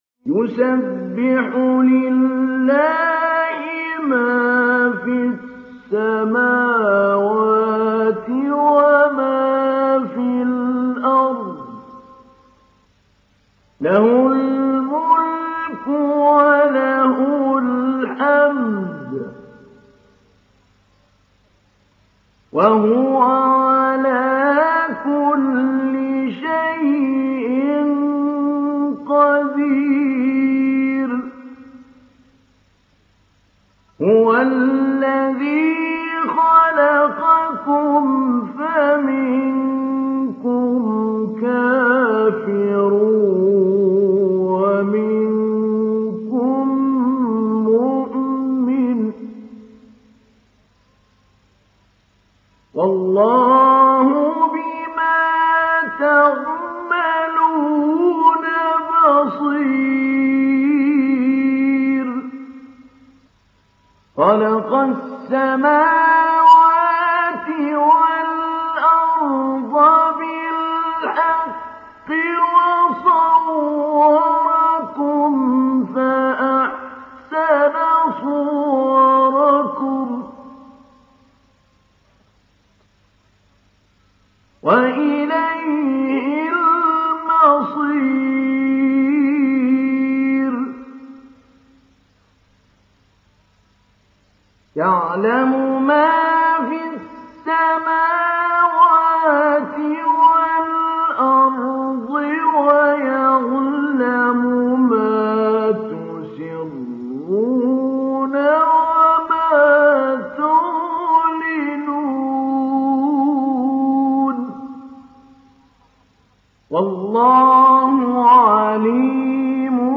Surat At Taghabun Download mp3 Mahmoud Ali Albanna Mujawwad Riwayat Hafs dari Asim, Download Quran dan mendengarkan mp3 tautan langsung penuh
Download Surat At Taghabun Mahmoud Ali Albanna Mujawwad